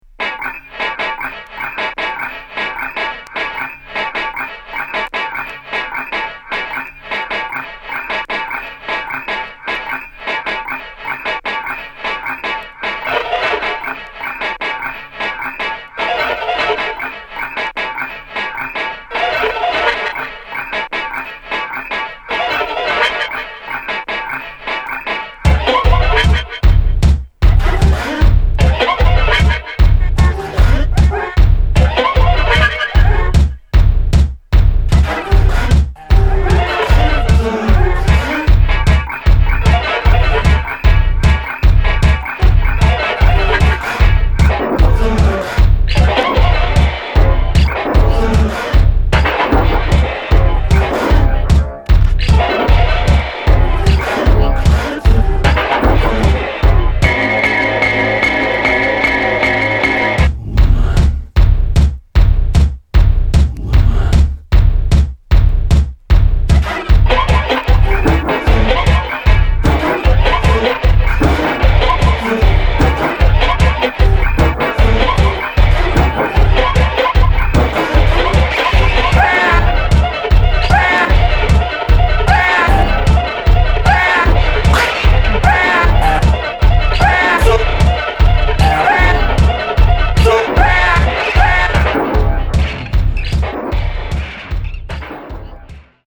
ミニマル　室内楽　空想民俗